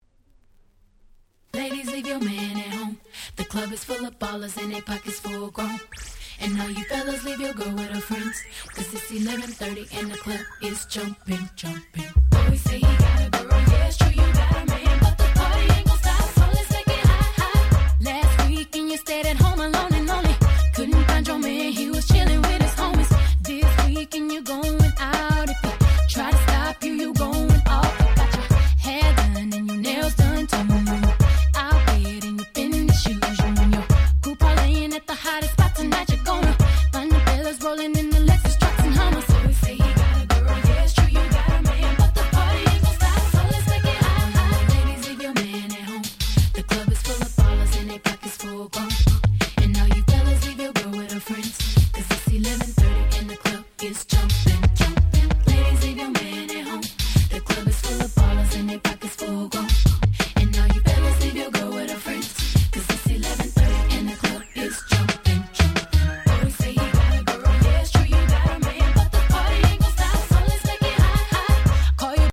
00' Super Hit R&B !!
キャッチーなサビとコーラスが最高なスーパーヒット！